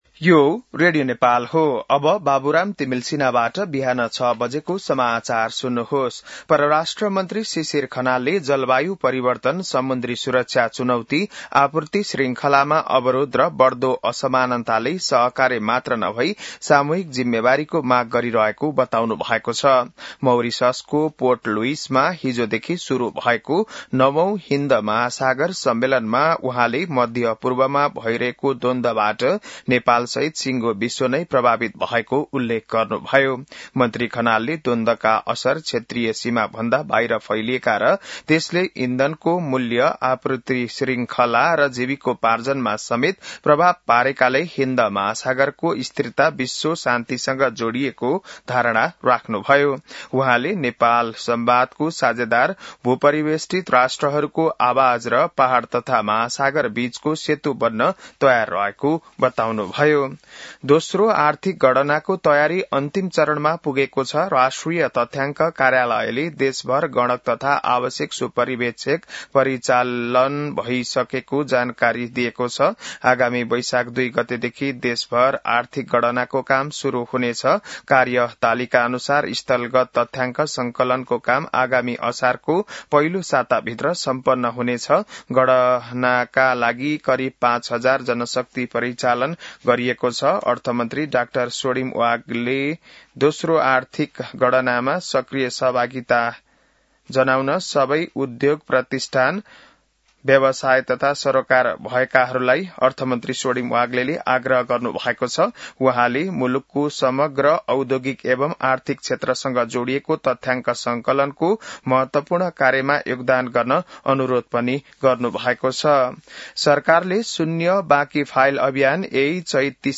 बिहान ६ बजेको नेपाली समाचार : २९ चैत , २०८२